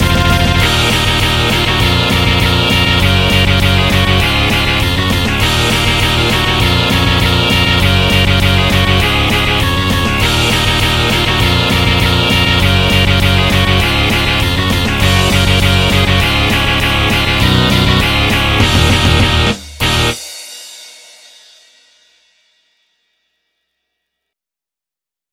MIDI 5.9 KB MP3 (Converted) 0.39 MB MIDI-XML Sheet Music
Edited using FL Studio & MidiEditor.